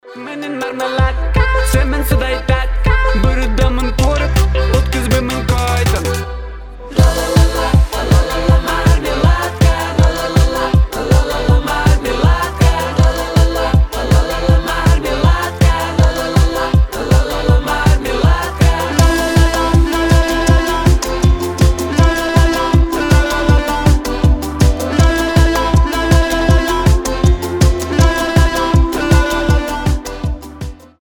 • Качество: 320, Stereo
веселые
смешные
Песня от блогера на казахском